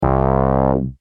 Free MP3 vintage Korg PS3100 loops & sound effects 7